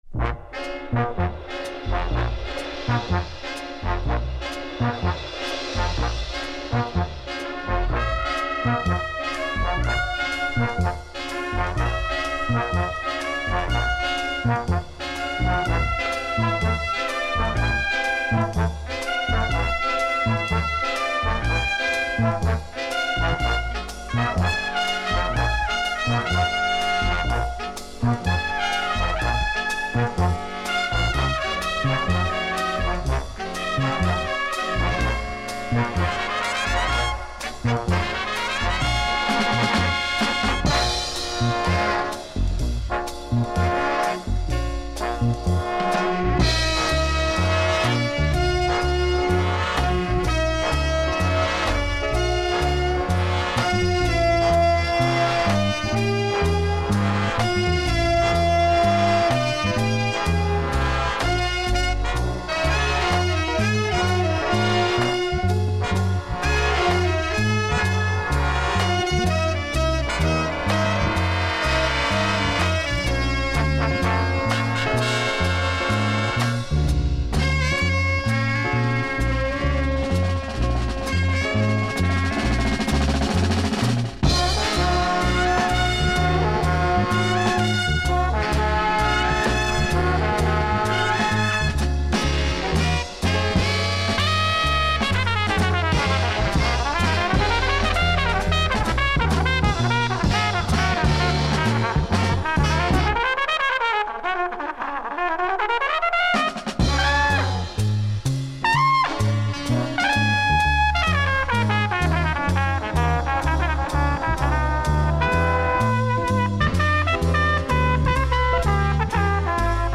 soaring horn lines and moving rhythm